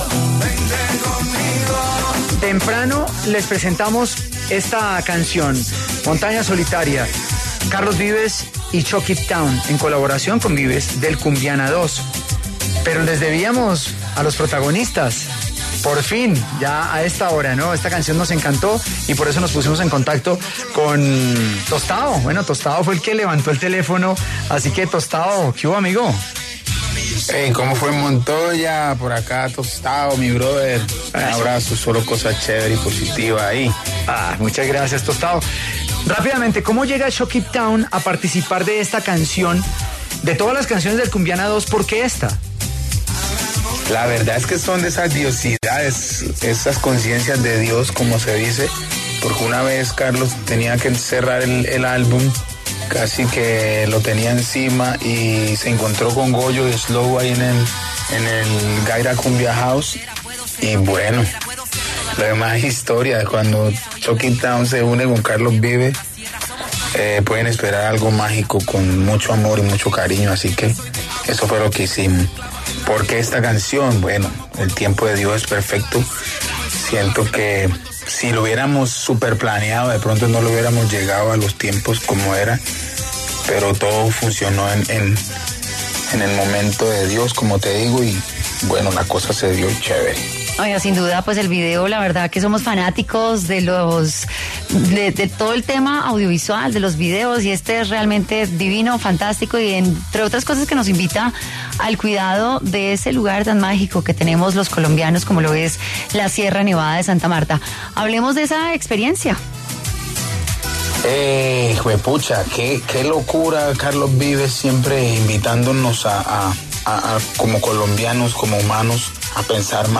Tostao, integrante de ChocQuibTown habló en los micrófonos de Contrarreloj de W Radio.